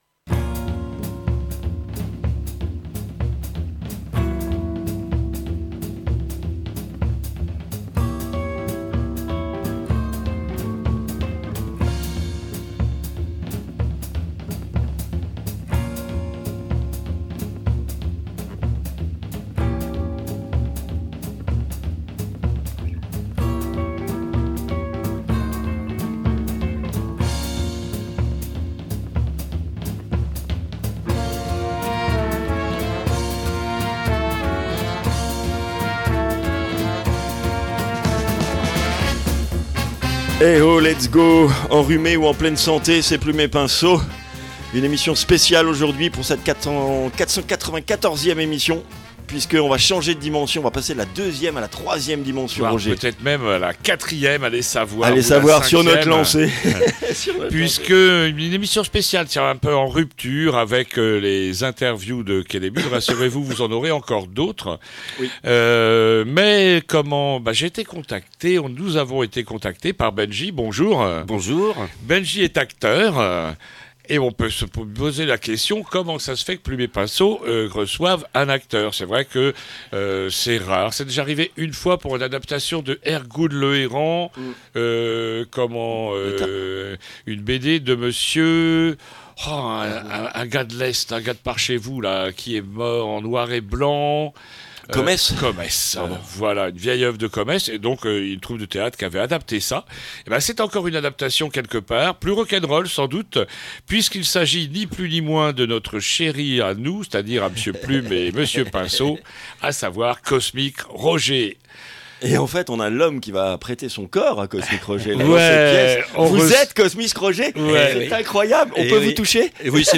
I - INTERVIEW